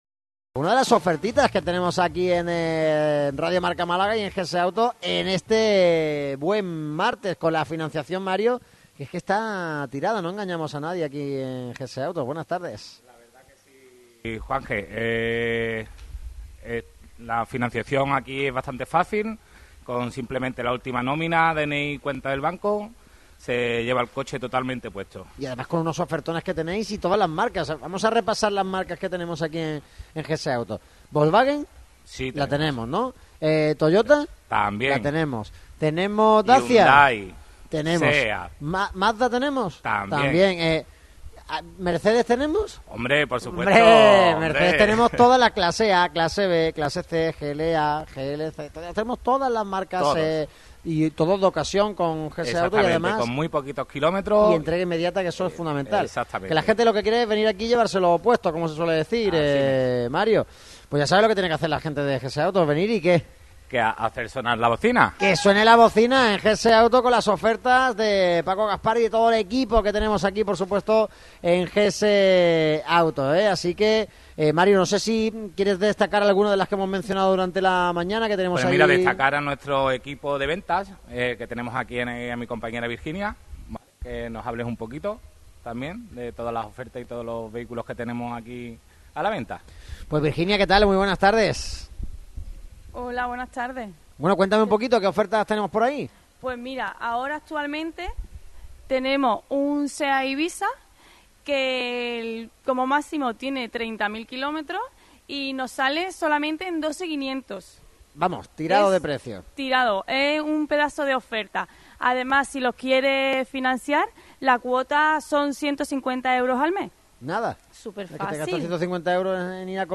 Este martes el equipo de Radio MARCA Málaga ha visitado las instalaciones de GS Autos en el Polígono de Santa Bárbara C/ Hnos Lumiere 17.